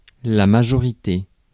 Prononciation
Synonymes âge adulte Prononciation France: IPA: [ma.ʒɔ.ʁi.te] Le mot recherché trouvé avec ces langues de source: français Traduction 1.